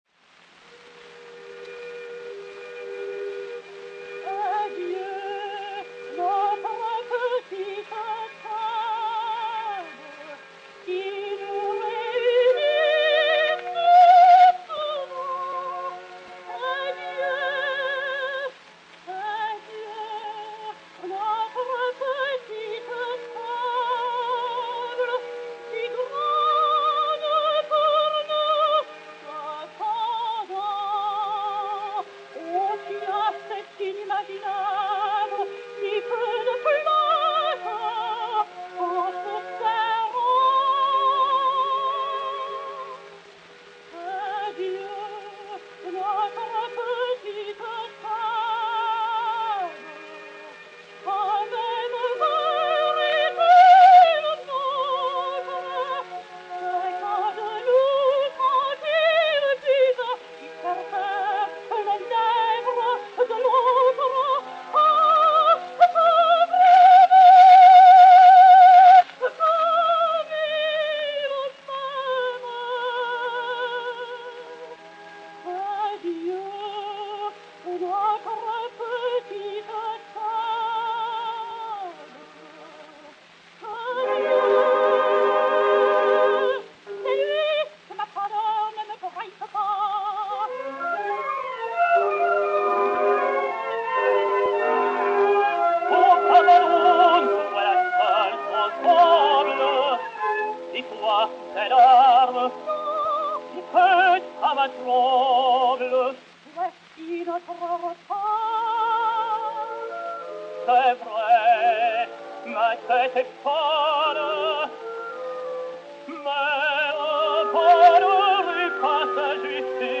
et Orchestre
Disque Pour Gramophone GC 34197, mat 5716h, enr. à Paris en 1908